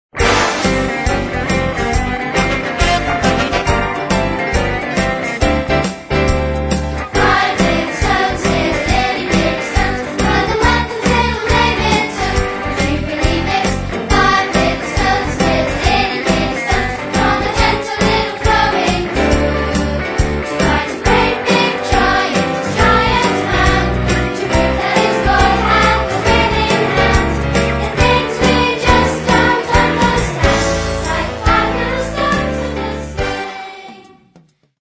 is an up-beat musical for Keystages 1 & 2